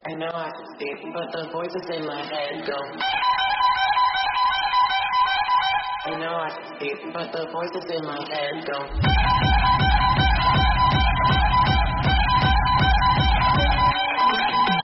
Meme Sound Effects